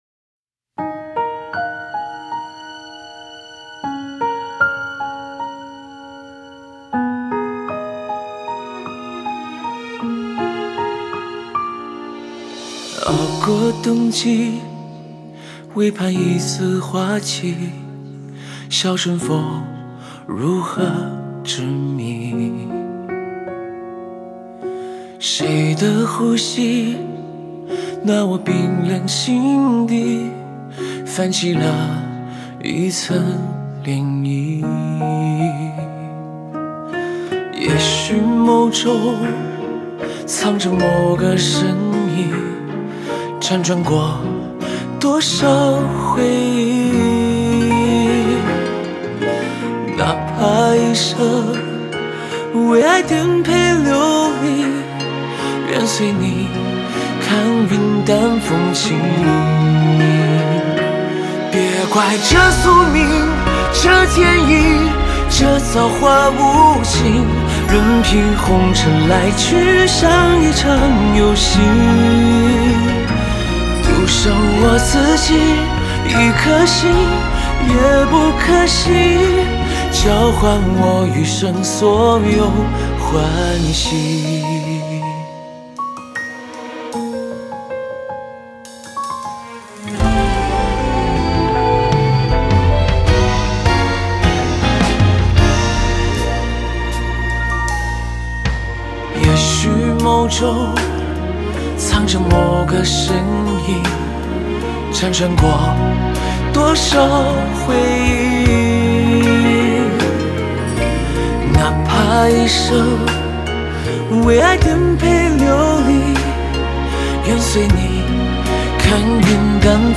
吉他
和声